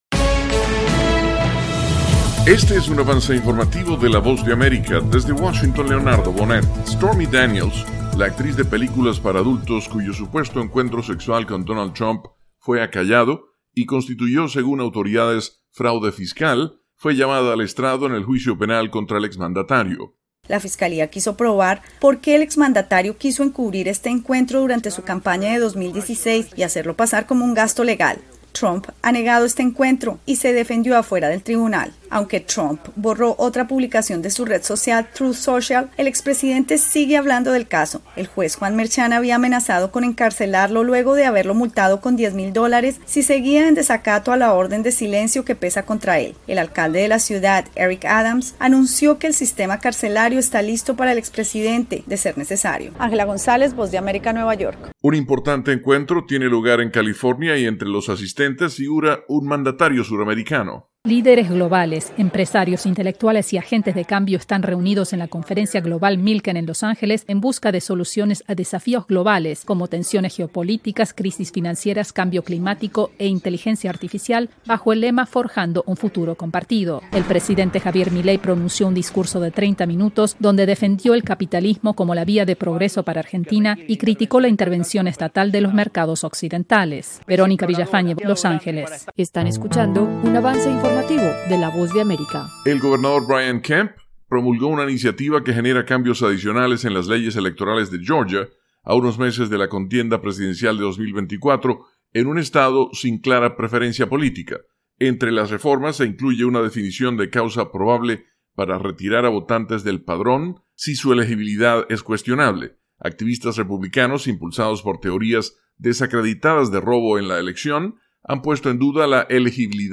El siguiente es un avance informativo presentado por la Voz de América